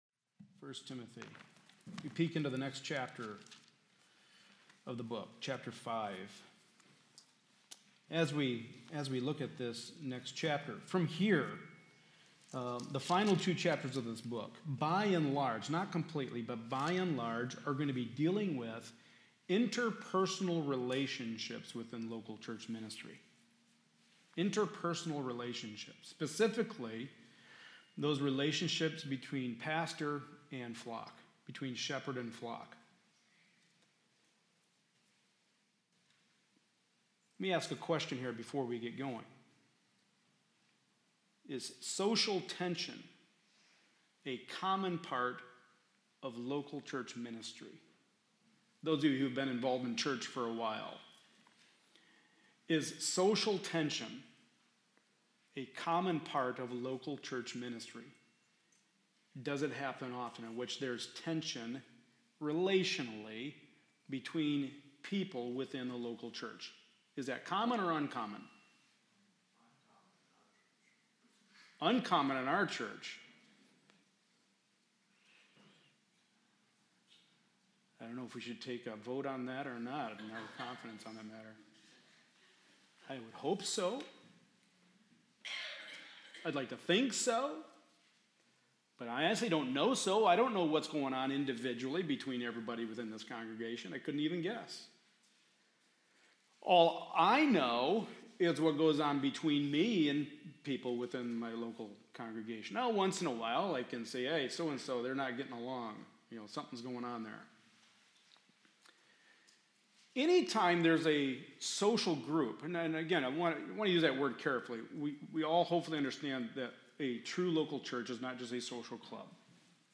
Passage: 1 Timothy 5: 1-2 Service Type: Sunday Morning Service